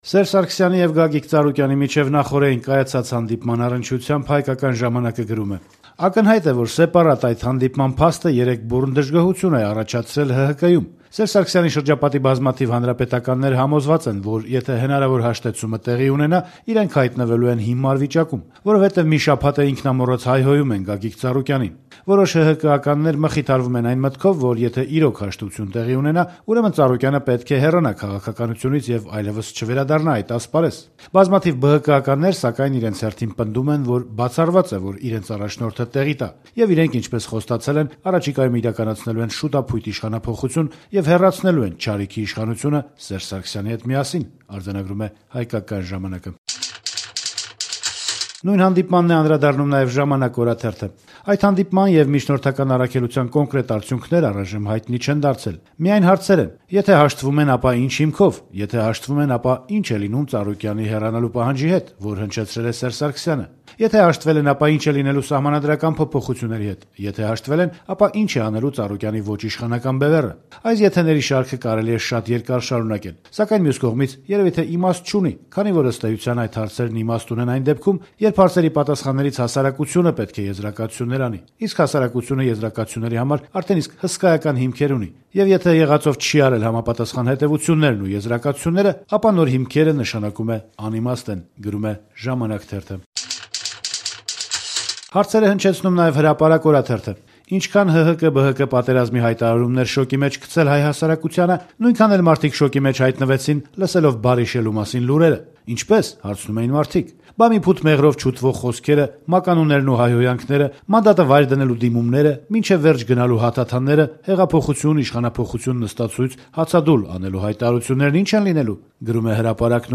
Մամուլի տեսություն